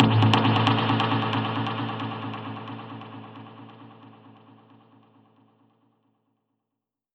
Index of /musicradar/dub-percussion-samples/134bpm
DPFX_PercHit_C_134-03.wav